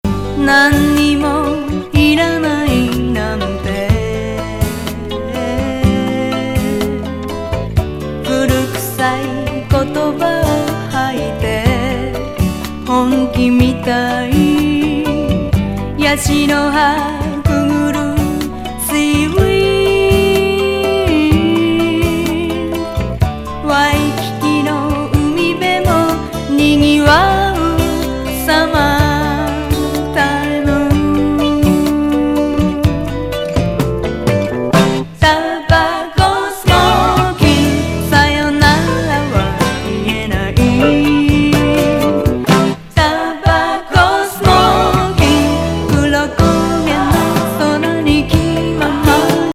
LA録音作。